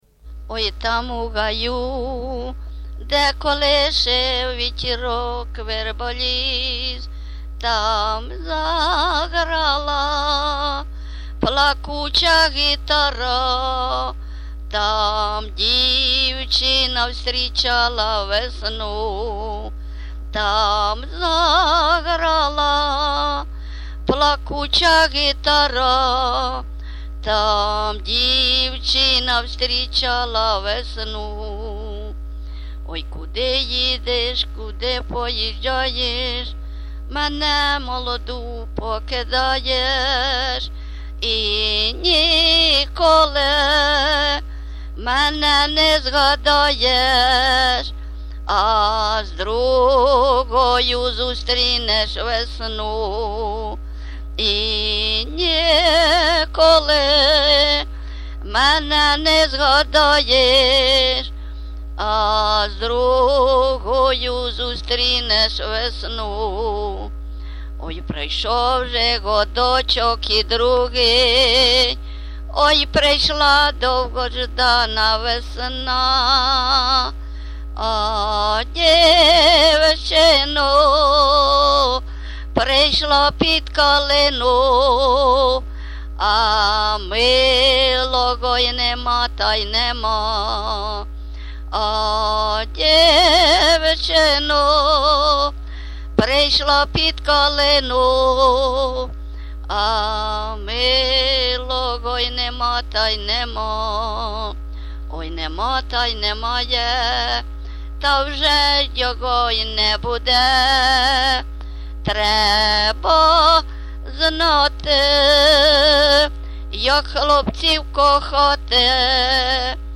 ЖанрРоманси, Сучасні пісні та новотвори
Місце записус. Нижні Рівні, Чутівський район, Полтавська обл., Україна, Слобожанщина